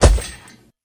Minecraft Version Minecraft Version snapshot Latest Release | Latest Snapshot snapshot / assets / minecraft / sounds / mob / ravager / step1.ogg Compare With Compare With Latest Release | Latest Snapshot
step1.ogg